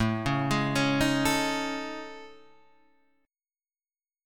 A Major 7th Flat 5th